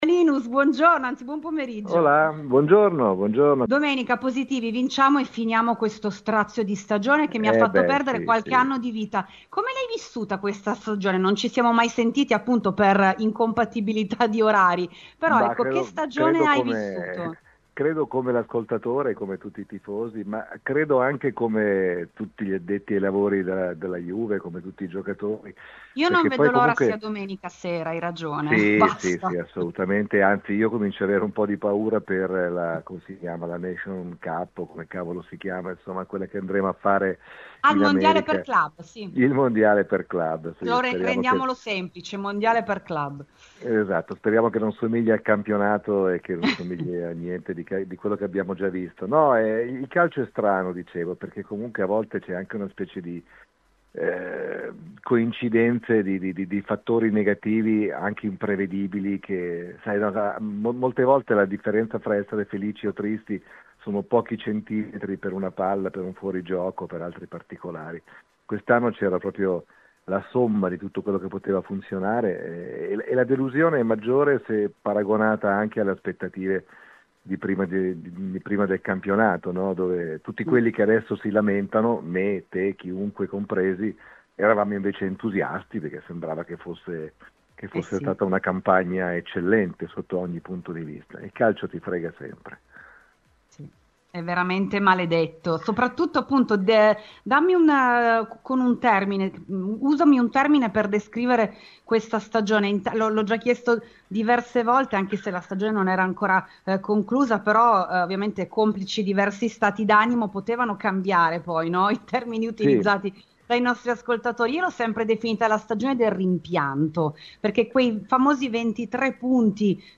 Ne è convinto anche Linus, tifoso juventino e direttore di Radio Deejay, intervenuto ai microfoni de "Il Club degli Inviati" su Radiobianconera.